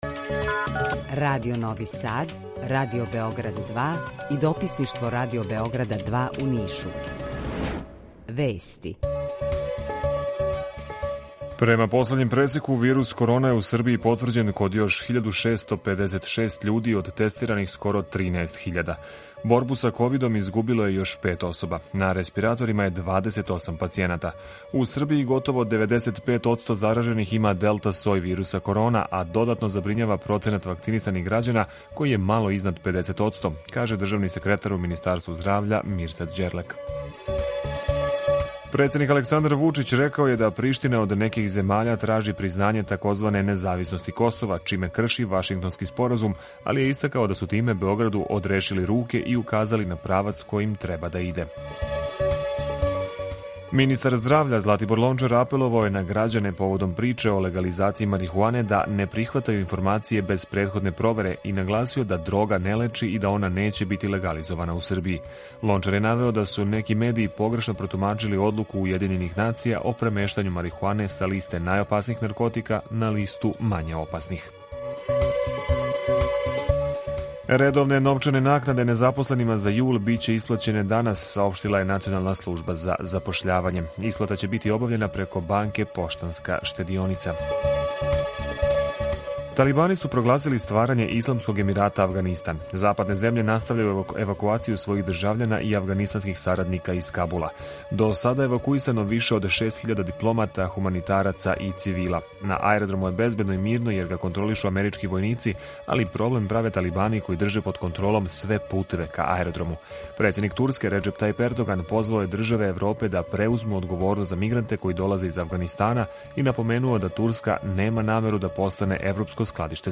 Укључење Радија Републике Српске
Јутарњи програм из три студија
У два сата, ту је и добра музика, другачија у односу на остале радио-станице.